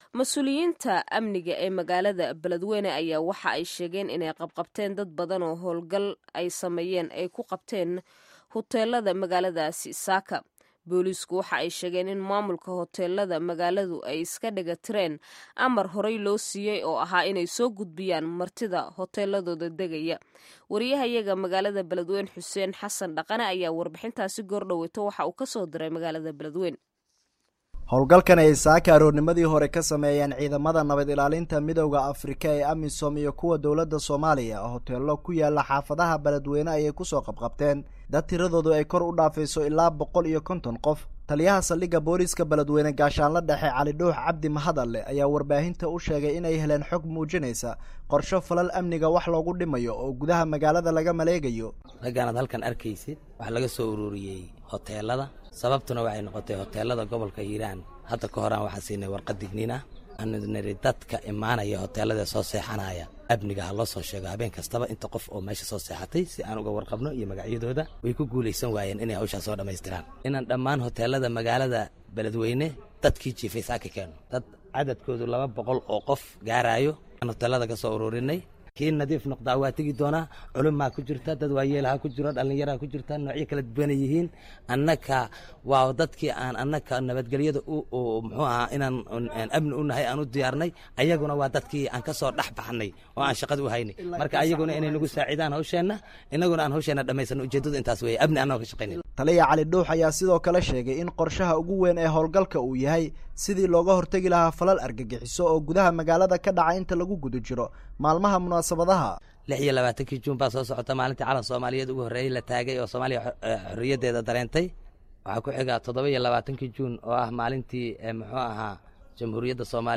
Warbixinta Hawlgalka Beledweyne